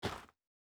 Shoe Step Gravel Hard D.wav